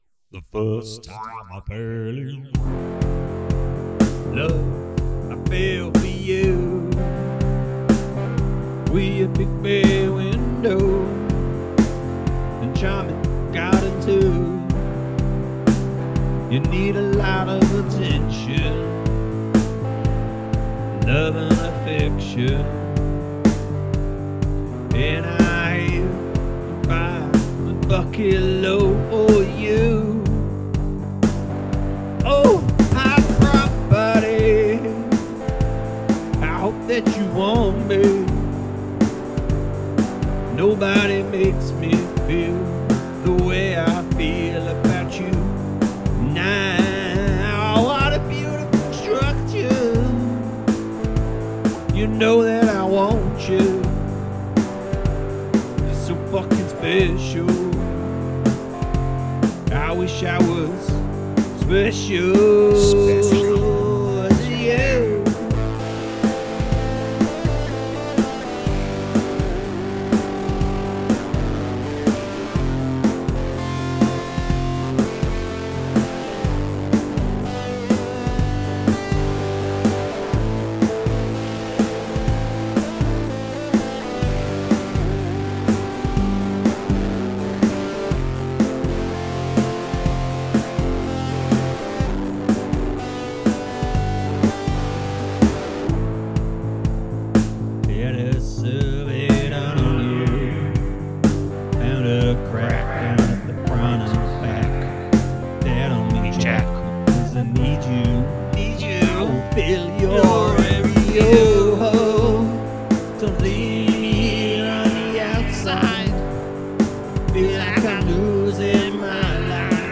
This song features many riffs, licks and whatever.